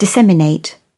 Транскрипция и произношение слова "disseminate" в британском и американском вариантах.